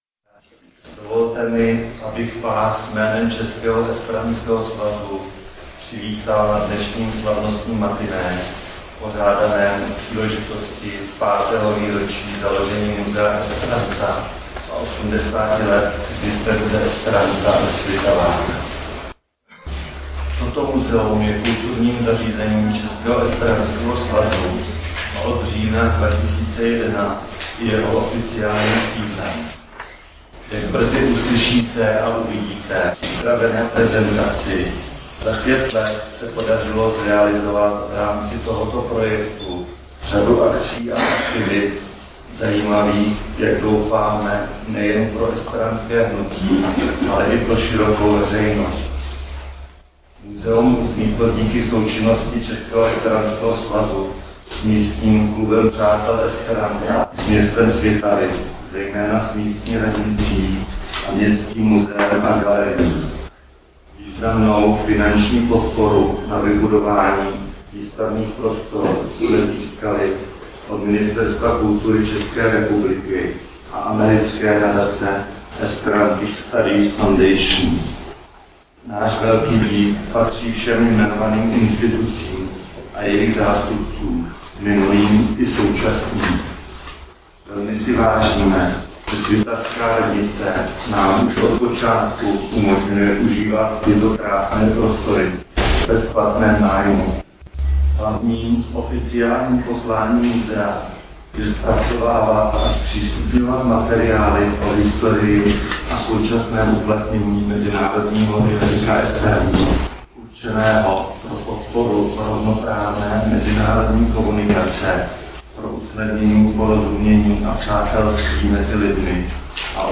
Oslavy 80 let esperantského klubu ve Svitavách a 5. výročí tamního Muzea esperanta, které proběhly 27. až 29. září 2013 v prostorách muzea, zachytila regionální televize i esperantská internetová televize Verda Stacio, která nabízí i záběry z odhalení památníku esperanta v Kopřivnici.
na slavnostním matiné